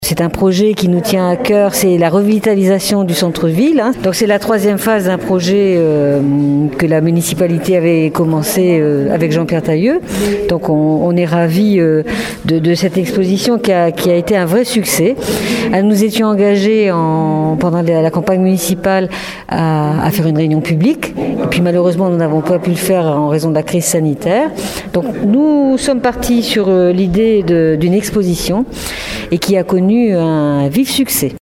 Cette exposition a connu un certain succès dont se félicite la maire Laurence Osta-Amigo qui a tenu à privilégier la concertation :